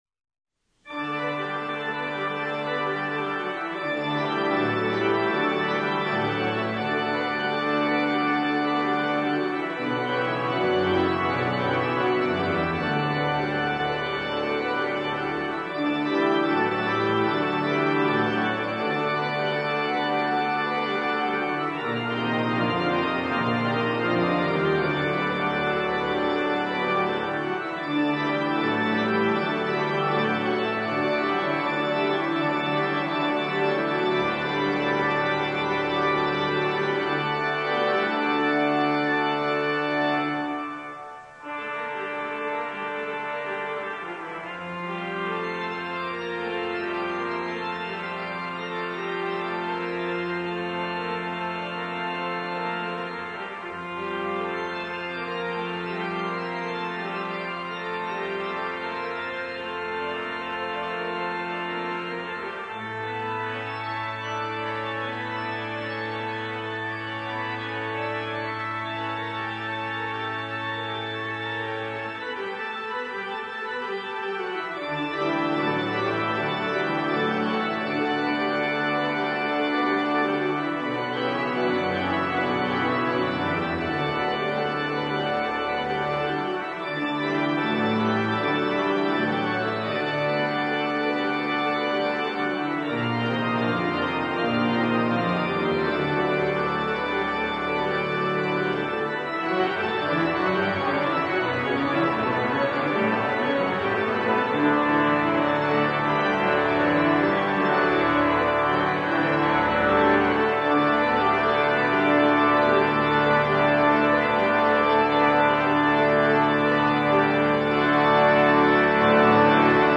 Voicing: Organ Solo